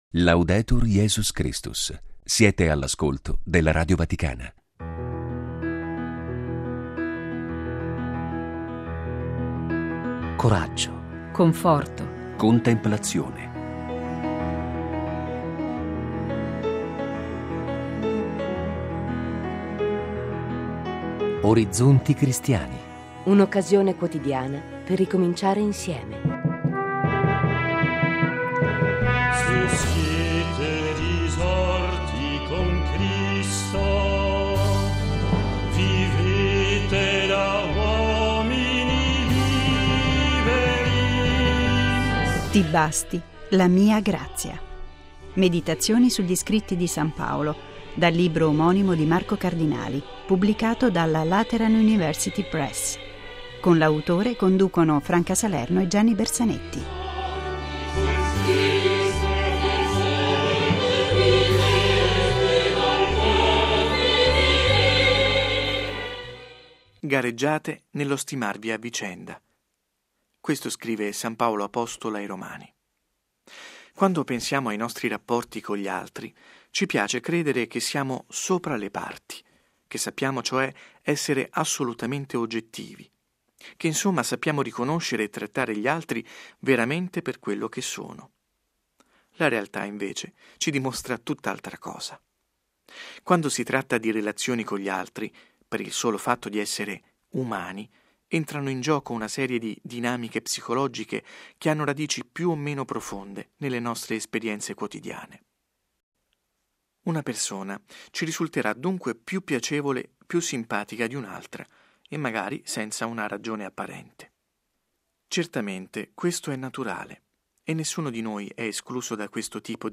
Tutti i giorni alle 7.15 e alle 23.45 Meditazioni scritte da Marco Cardinali